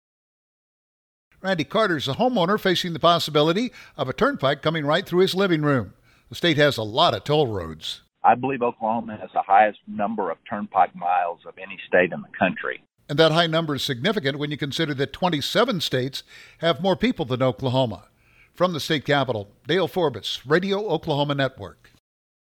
provide details.